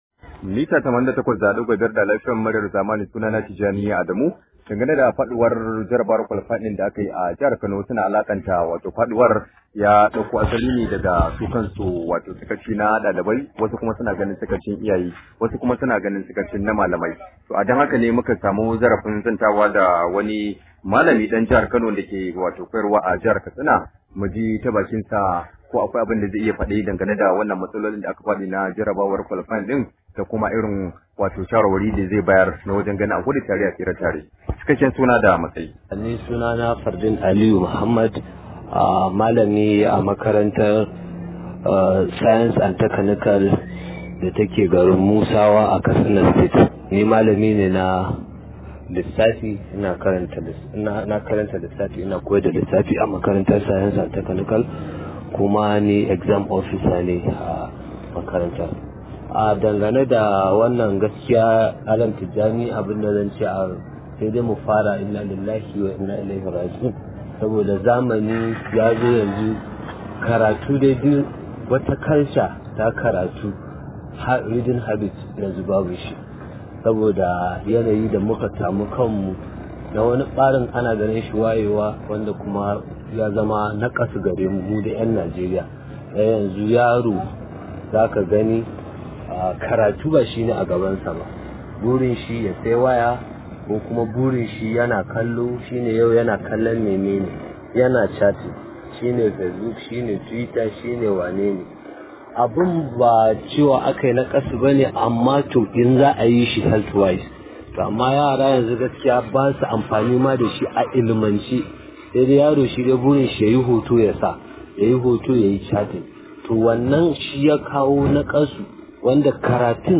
Rahoto: Rike manyan wayoyi ya janyo dalibai faduwa Jarabawa – Malami